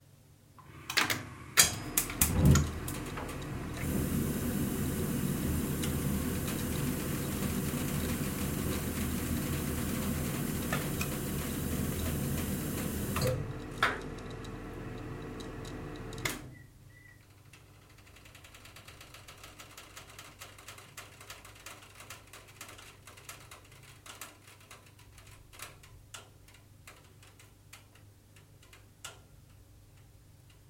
Звуки газовой колонки
На этой странице собраны звуки работающей газовой колонки – от розжига до равномерного гудения.
Система подачи горячей воды